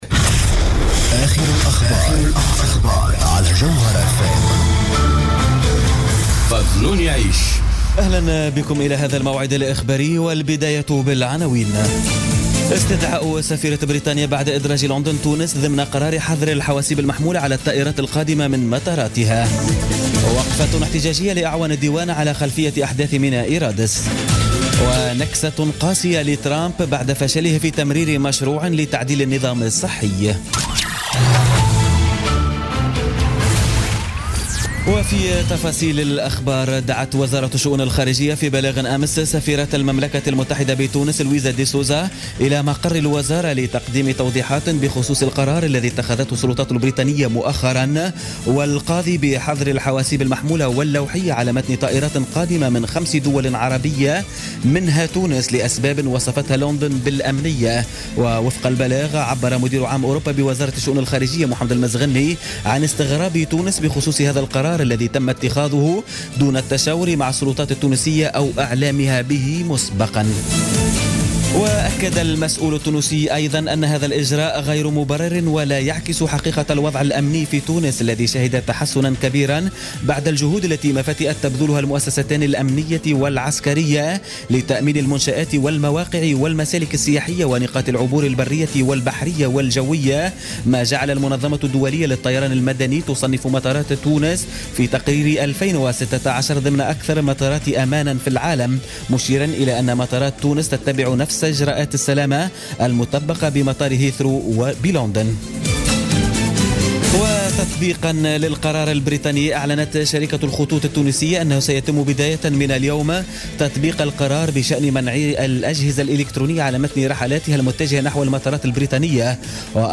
نشرة أخبار منتصف الليل ليوم السبت 25 مارس 2017